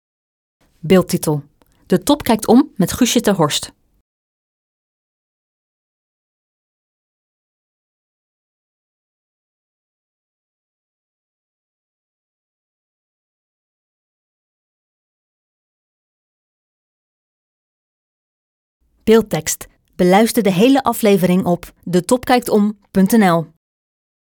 Je kunt het interview met Guusje ter Horst op drie manieren volgen: